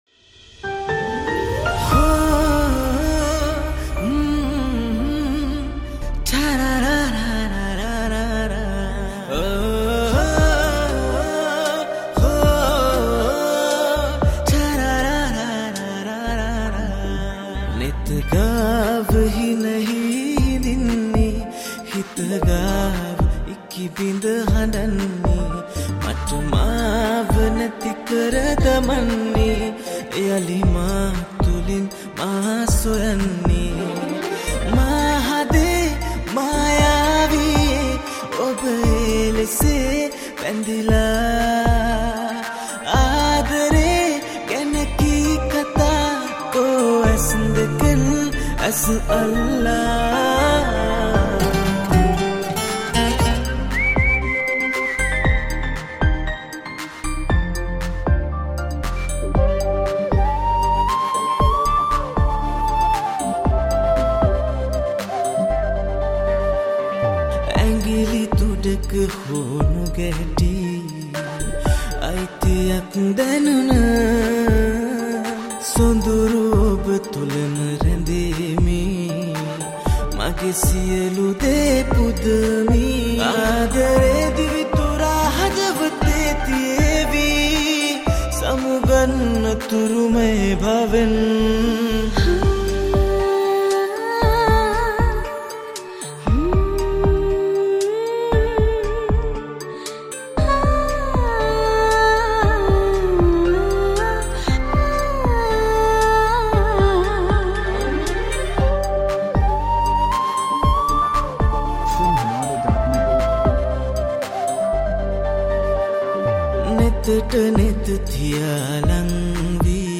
Category: Teledrama Song